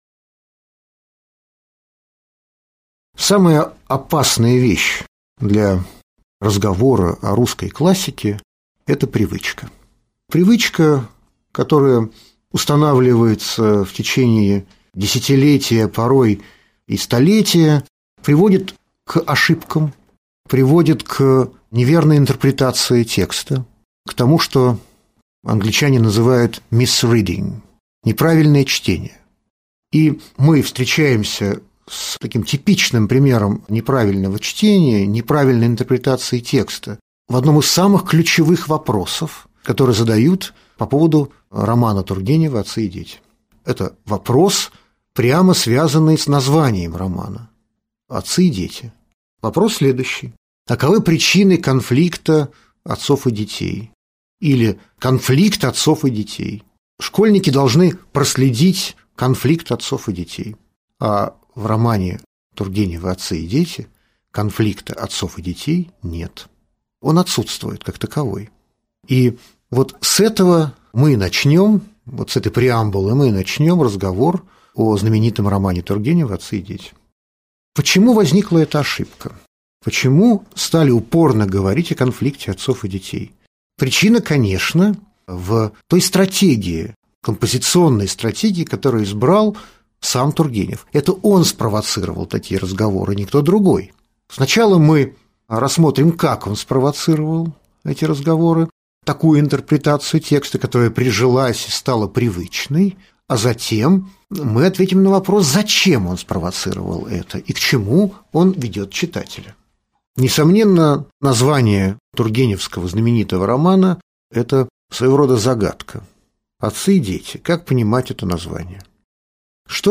Аудиокнига Лекция «Отцы и дети»: конфликт или органическая связь?»